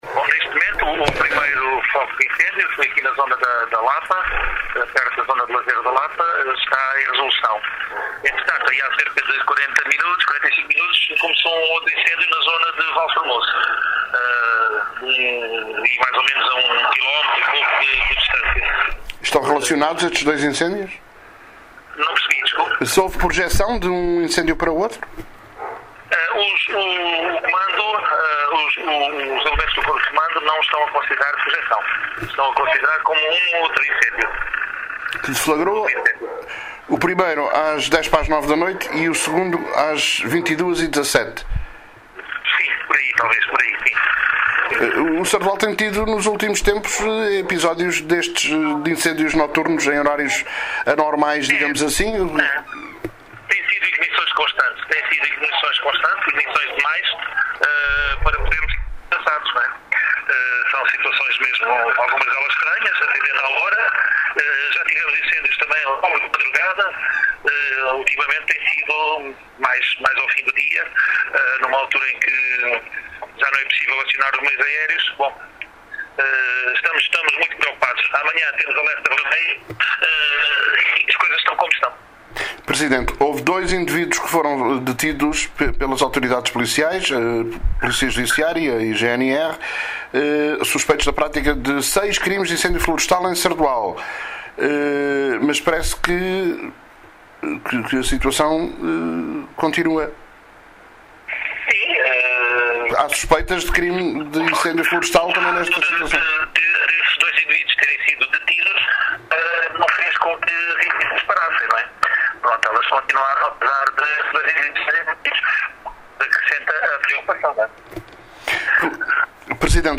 Miguel Borges, presidente da Câmara de Sardoal, fez às 23:00 um ponto de situação sobre estas ignições no concelho de Sardoal.